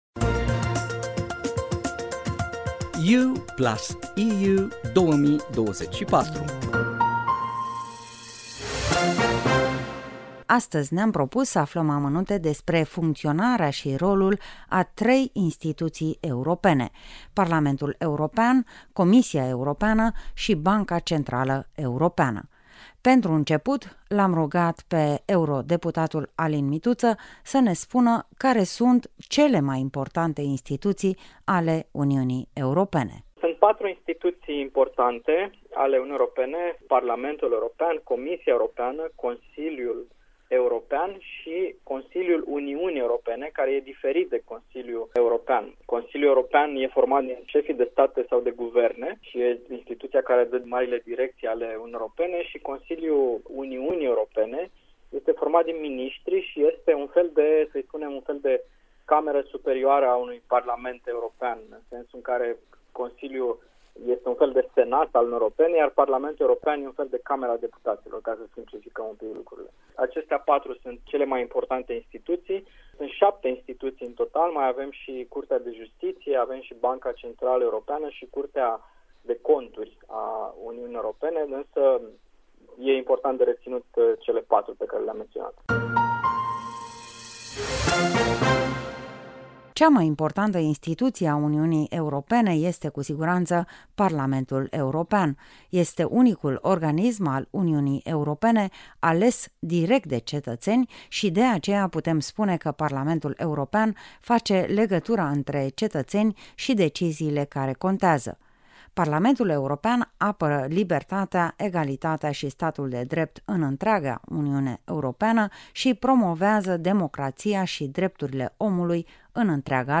Astăzi vorbim cu eurodeputatul Alin Mituţă despre Parlamentul European, Comisia Europeană şi Banca Centrală Europeană şi aflăm ce reprezintă aceste instituţii şi ce atribuţii au la nivelul Uniunii Europene.